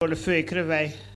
Soullans
Locutions vernaculaires